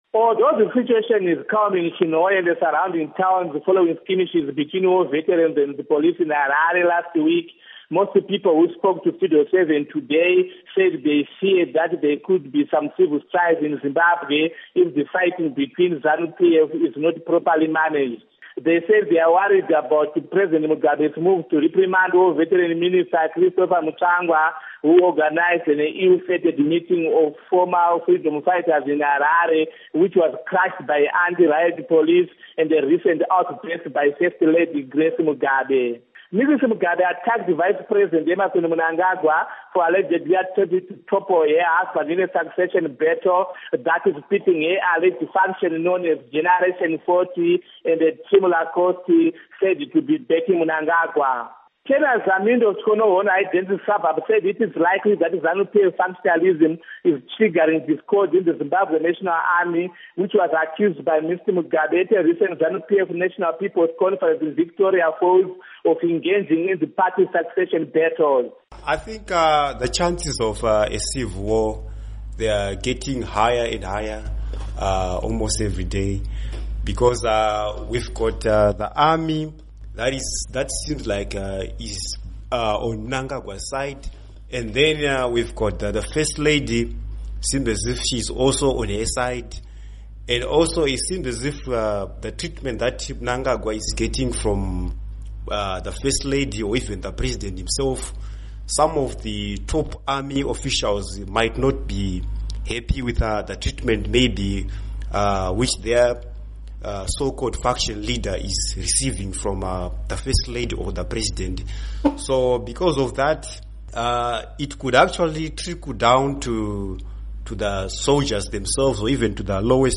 Report on Civil Strife